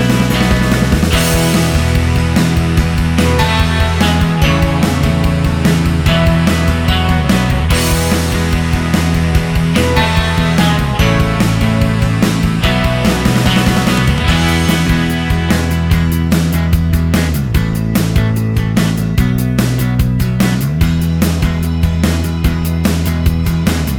No BV Count Rock 4:28 Buy £1.50